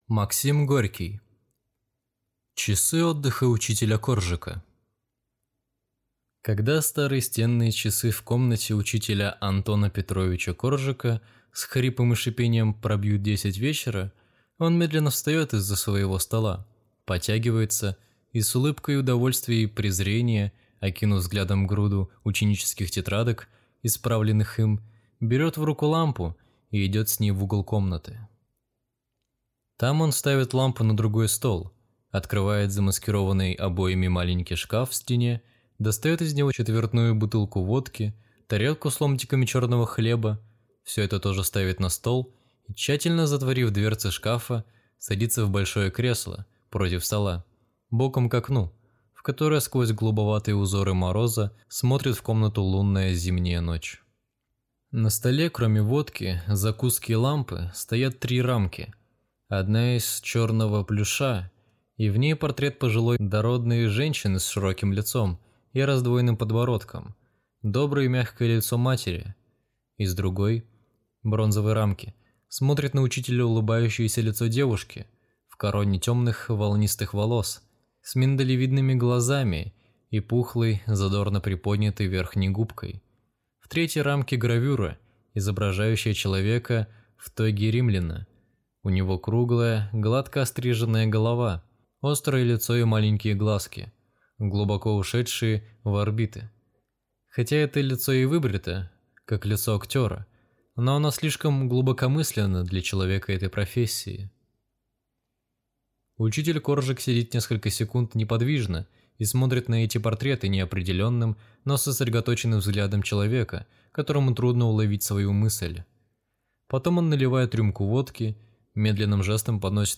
Аудиокнига Часы отдыха учителя Коржика | Библиотека аудиокниг